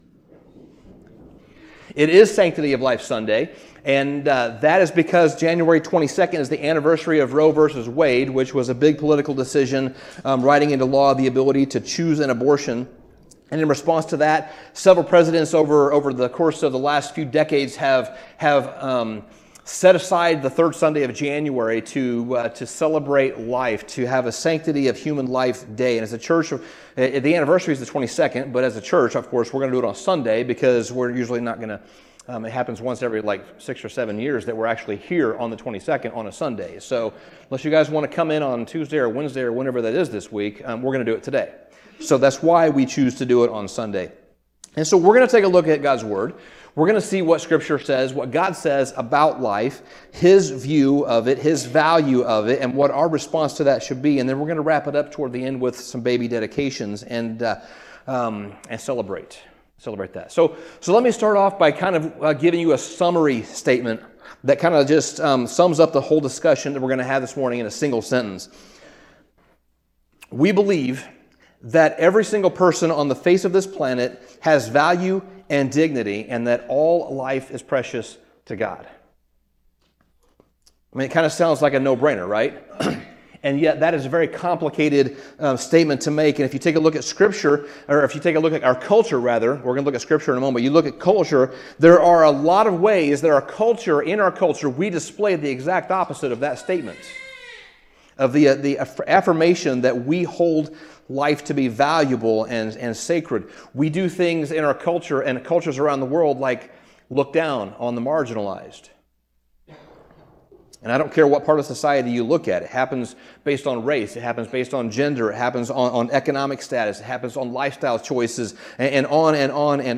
We want to remember the value that God places on human life, and how we should value it as well. In this special service, we take a look at what the Bible says about God’s view of life, what that means, and what our response should be. And then we wrap it up with a Baby Dedication ceremony, celebrating the new lives that have been born recently.